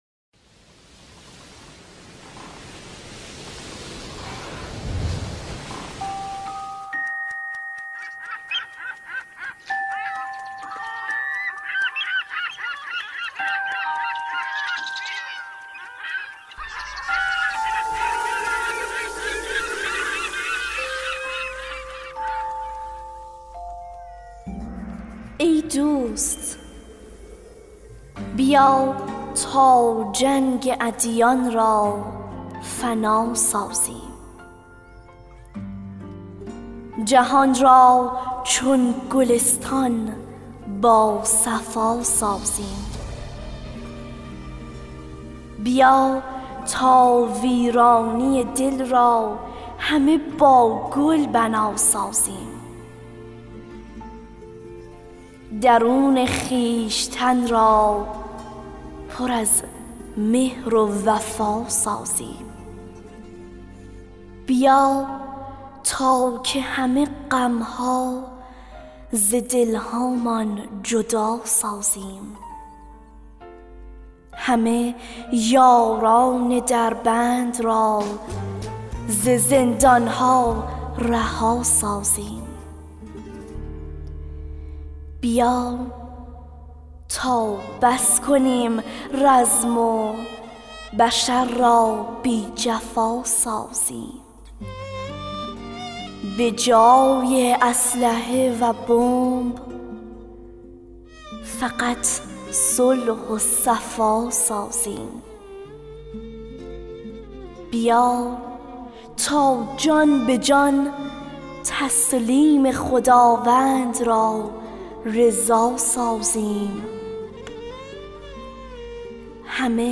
دکلمه اشعار همراه با موسیقی